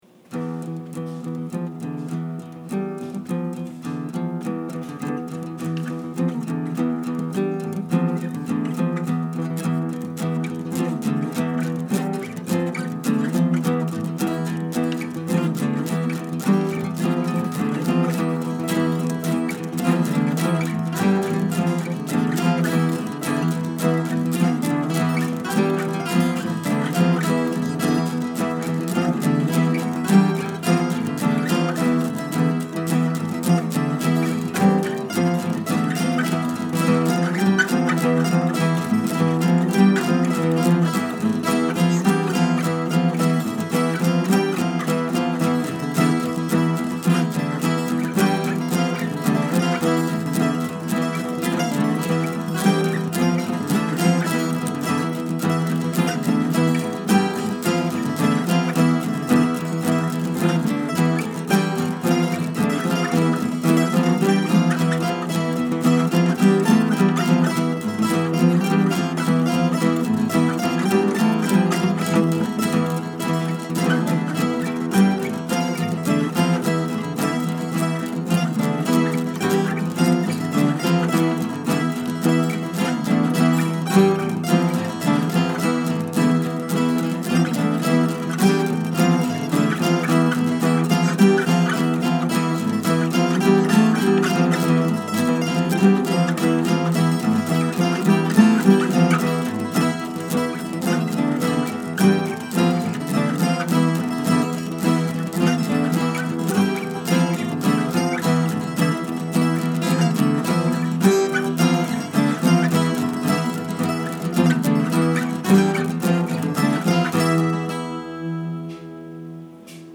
Recoded on my phone in the kitchen, with my laundry going in the background. Used a different application, the DAW MultiTrack.
This song has five: four guitars and a mandolin. It's my experiment with a different scale.